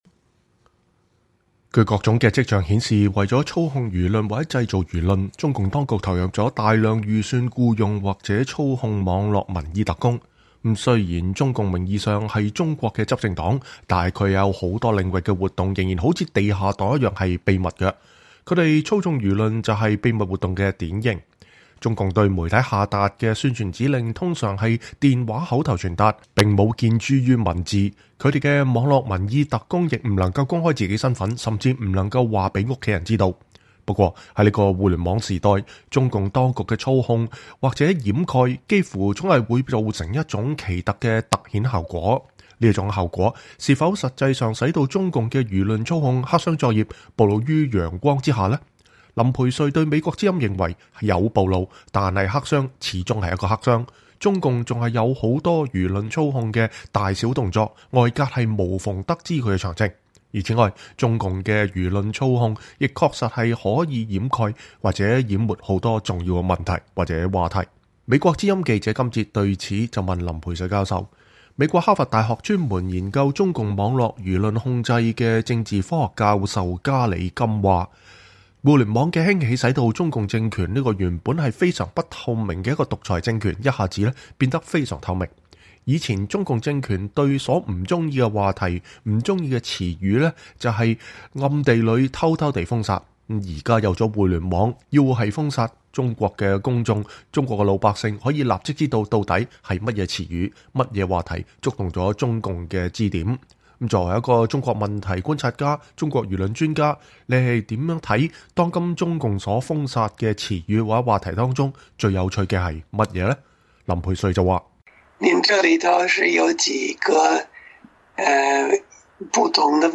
專訪林培瑞（4）：談當今中國禁忌詞與話題
以下是美國之音採訪林培瑞教授記錄的第四部分。